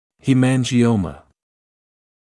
[ˌhiːˌmænʤɪ’əumə][ˌхиːˌмэнджи’оумэ]гемангиома